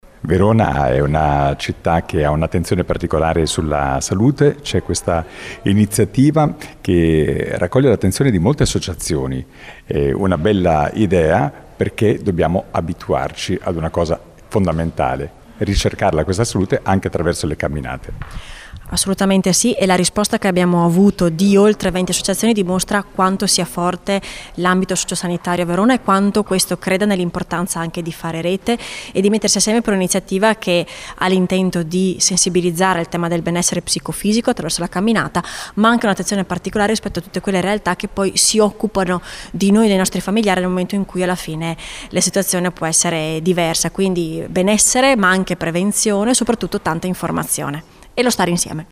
Al nostro microfono Elisa Dalle Pezze, presidente della Seconda Circoscrizione: